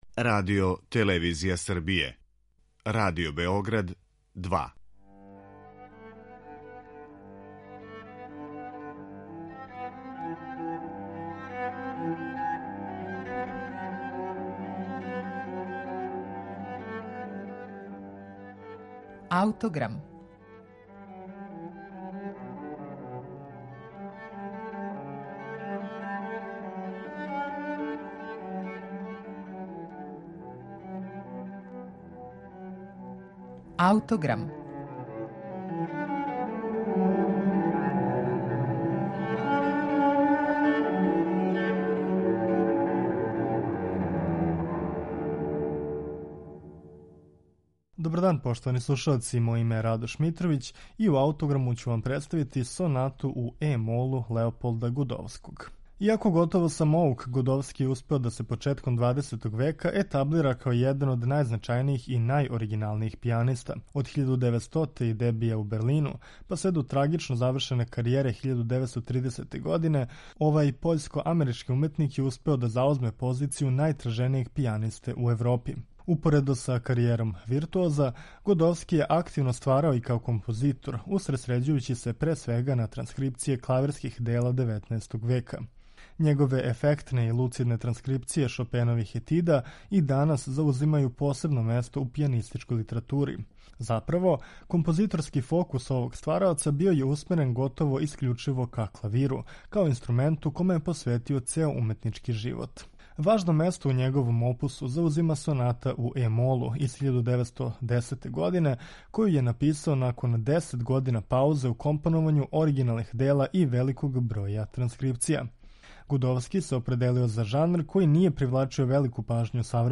Леополд Годовски: Соната за клавир
Поред различитих транскрипција за клавир, Соната у е-молу представља фокалну тачку његовог стваралаштва и својом формалном инвентивношћу указује на изузетну композиторску спретност и луцидност. Сонату у е-молу Леополда Годовског слушаћете у извођењу Марка Андреа Амлена.